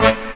68_09_stabhit-A.wav